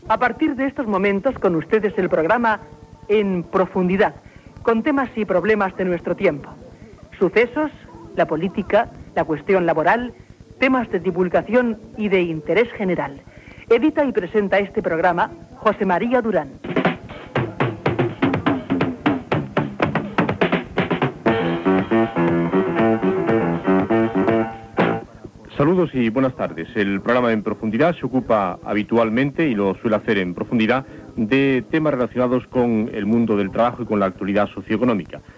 Careta del programa i inici de l'espai.
Informatiu